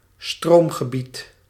Ääntäminen
Ääntäminen France: IPA: [sɛk.tœʁ] Haettu sana löytyi näillä lähdekielillä: ranska Käännös Konteksti Ääninäyte Substantiivit 1. sector {f} 2. deelgebied 3. lichtnet 4. stroomgebied {n} sähkö Suku: m .